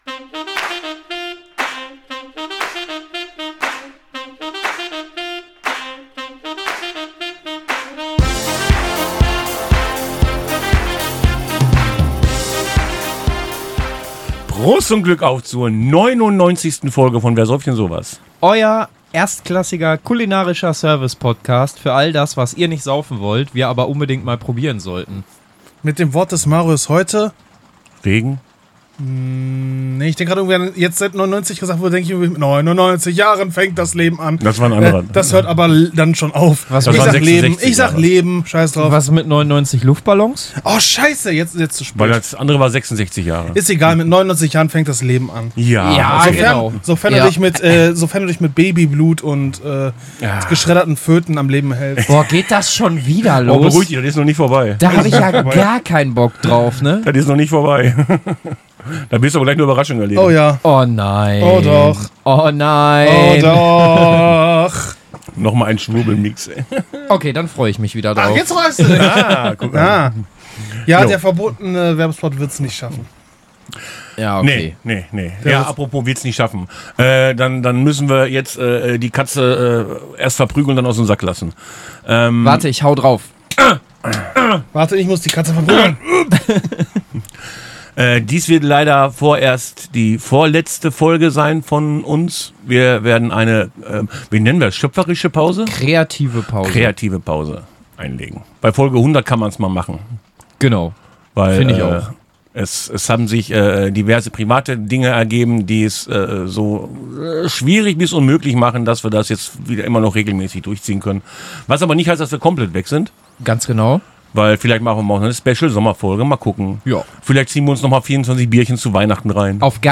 Und dieses Mal mit Rülpsern Viel Spaß mit dieser Folge!